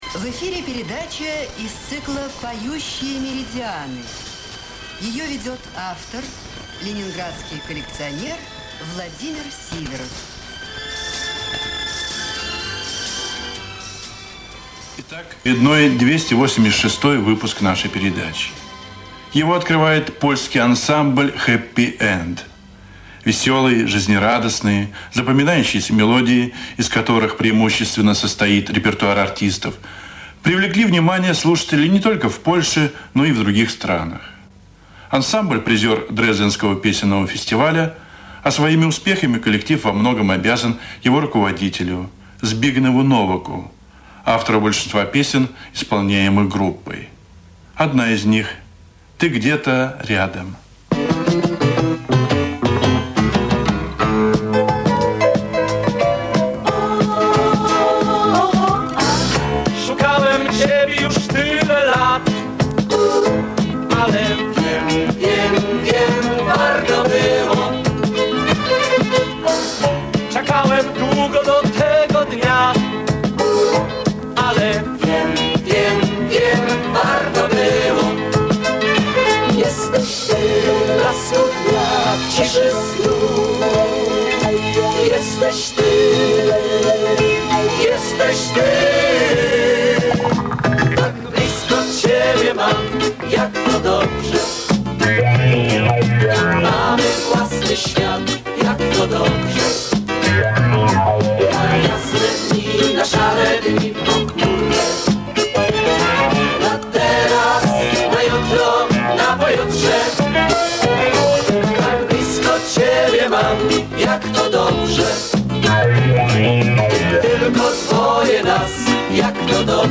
Радио "Маяк". Музыкальная передача "Поющие меридианы" (286-й выпуск).
Запись с радиоприёмника.
Оцифровка старой ленты.